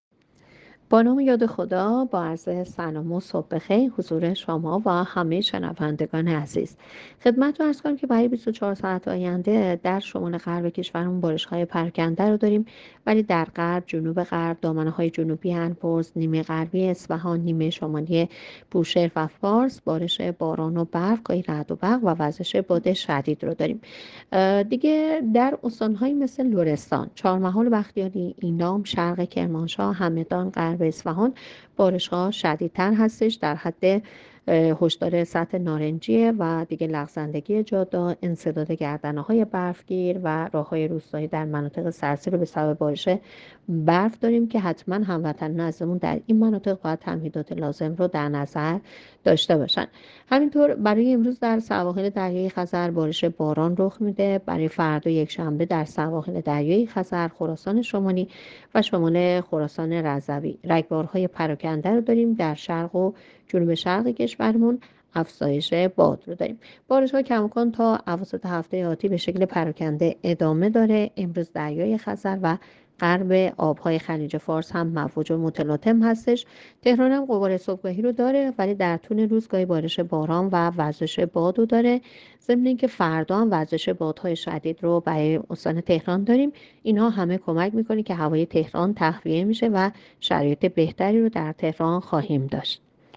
گزارش رادیو اینترنتی پایگاه‌ خبری از آخرین وضعیت آب‌وهوای ۸ دی؛